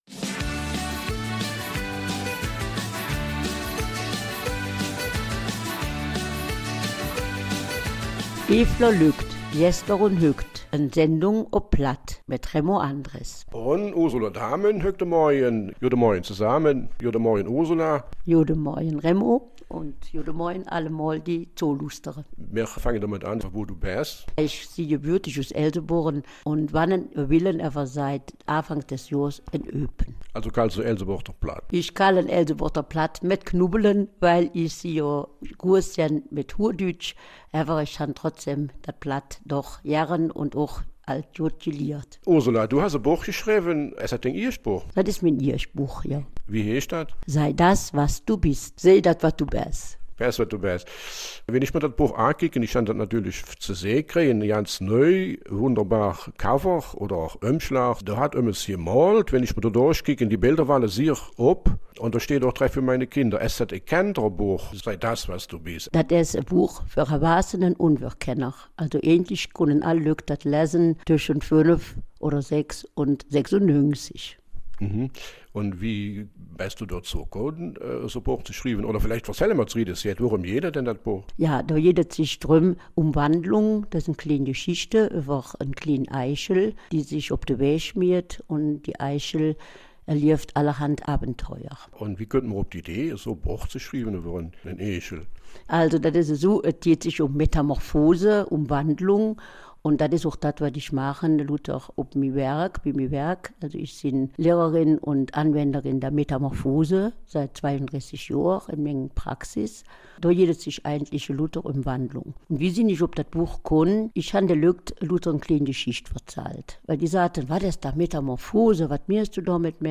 Eifeler Mundart - 28. September